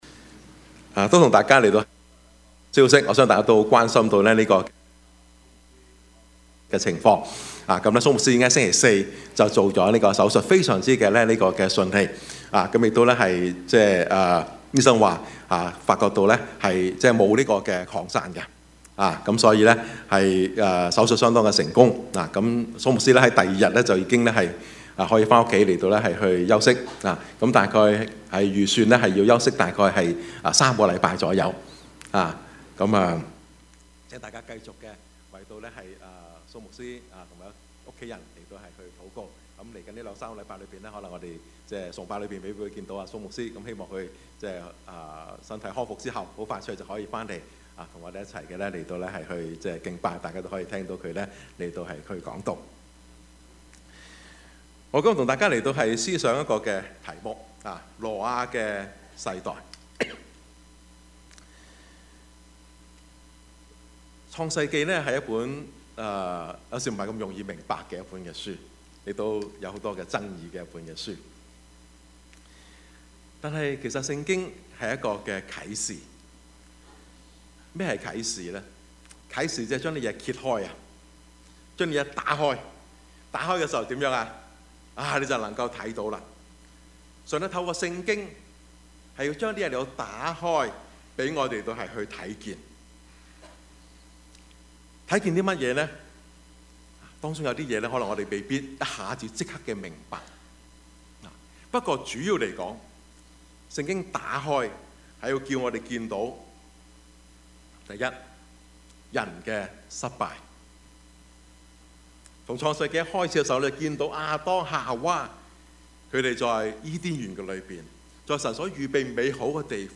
Service Type: 主日崇拜
Topics: 主日證道 « (福音主日) 女人唔易做 日光下的方舟 »